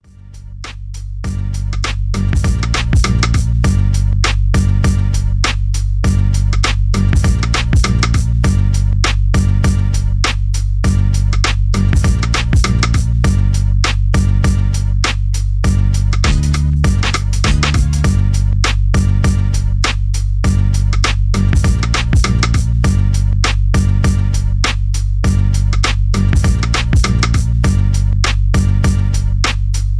Hip hop beat